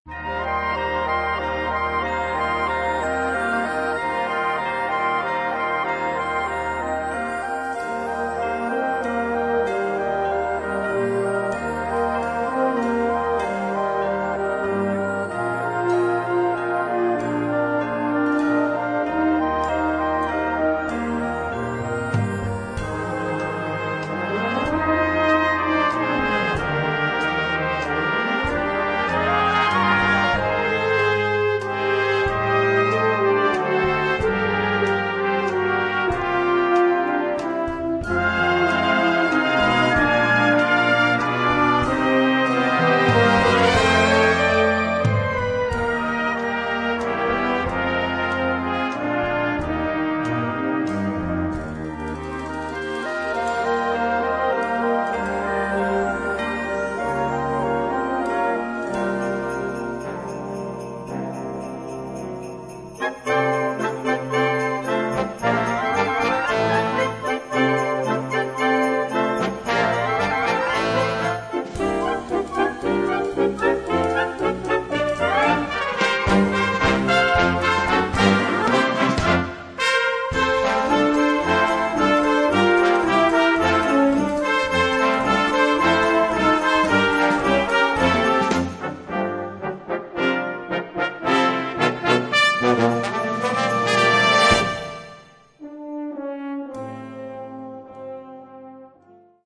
Gattung: Weihnachts-Medley
Besetzung: Blasorchester